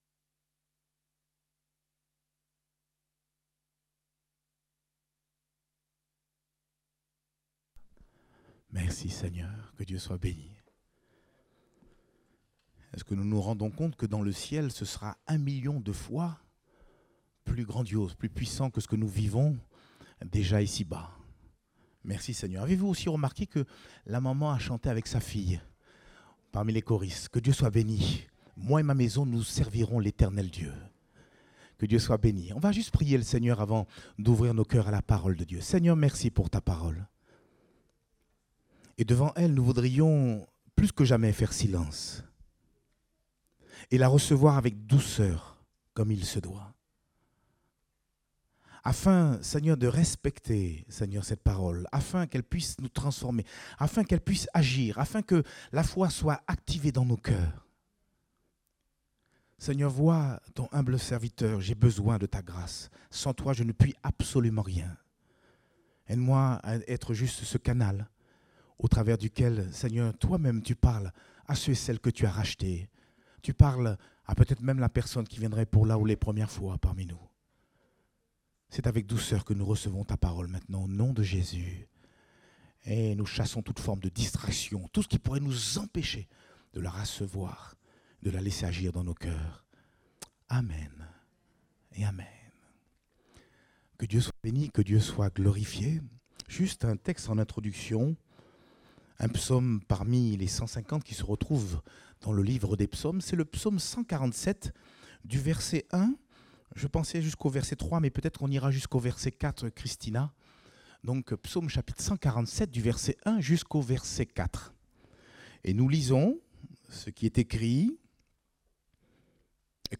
Date : 22 octobre 2023 (Culte Dominical)